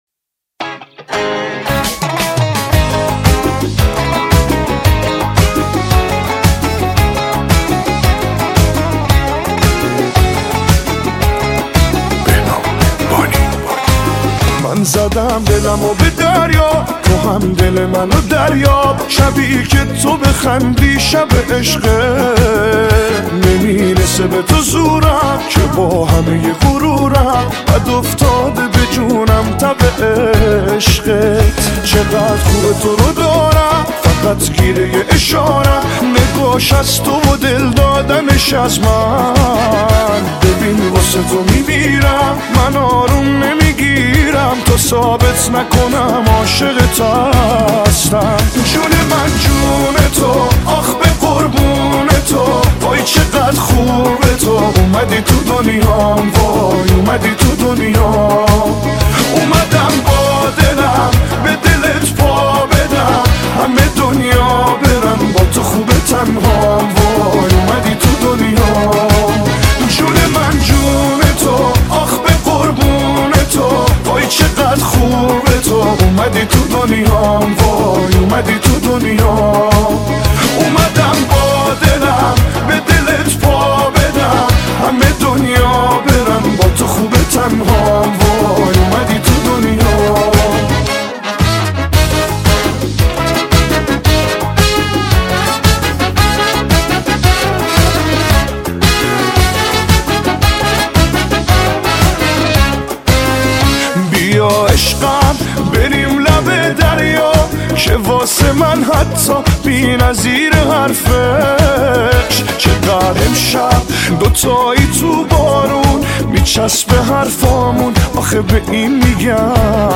پاپ شاد رقص عاشقانه